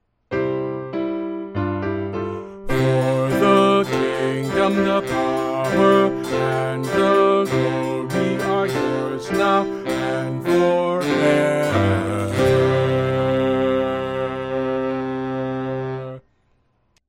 Voice | Downloadable